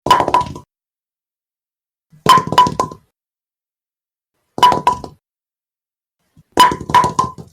Single Bowling Pin Noises
Single Bowling Pin Noises.mp3